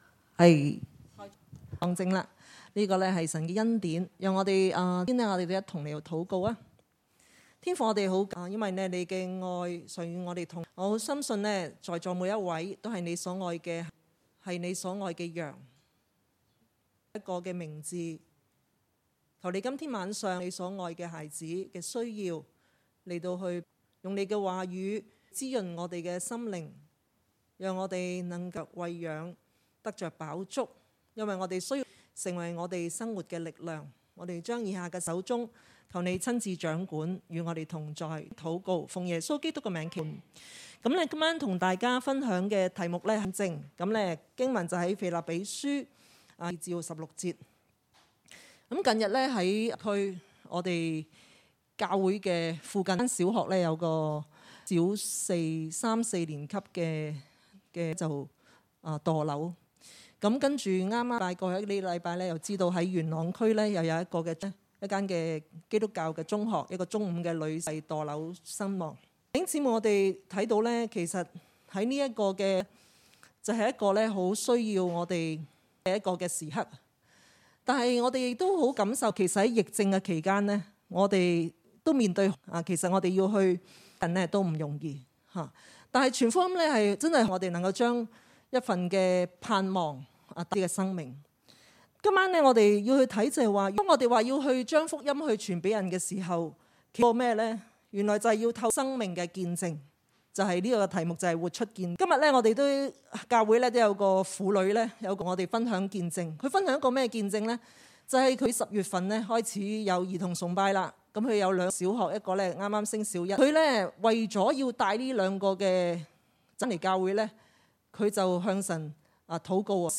2021年10月10日晚堂信息